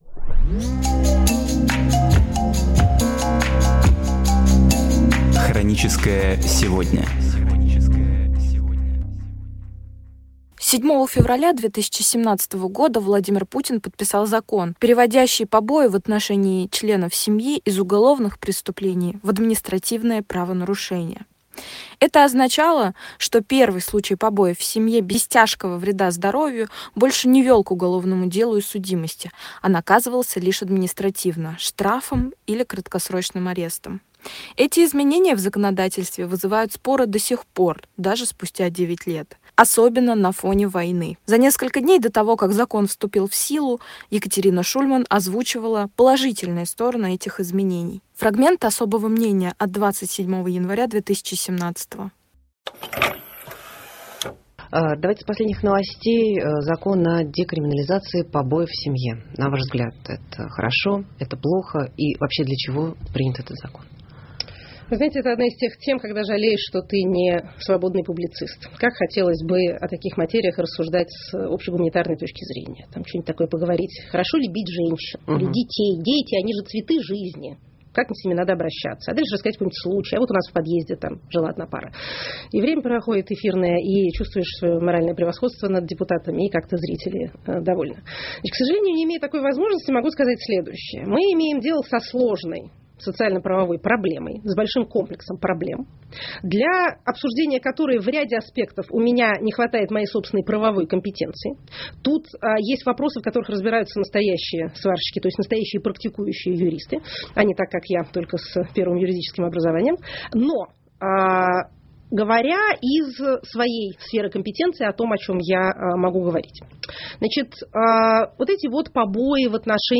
Программы из архива «Эха Москвы»